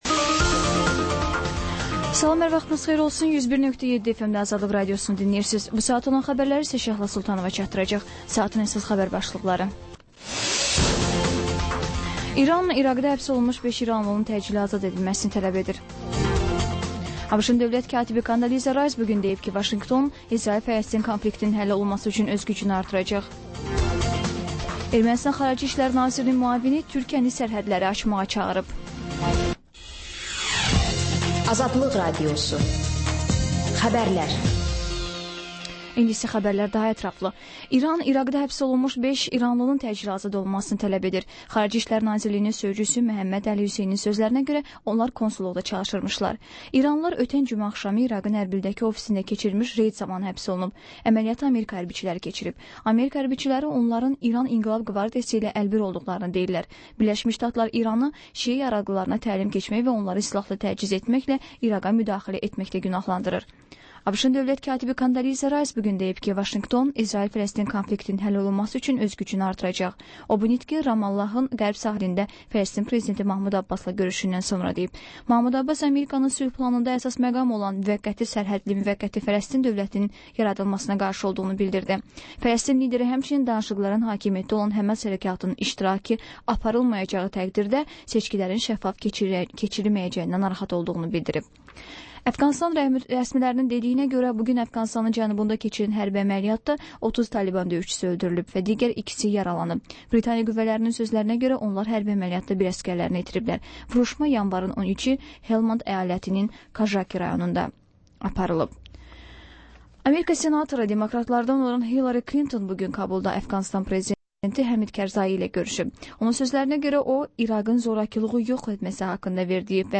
Xəbərlər, reportajlar, müsahibələr. İZ: Mədəniyyət proqramı.